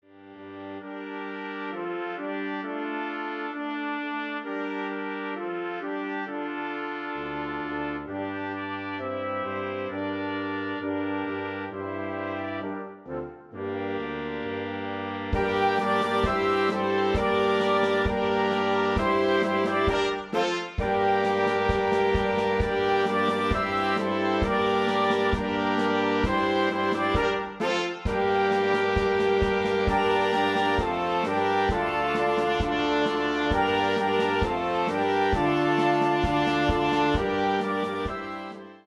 Instrumentation: standard Wind Band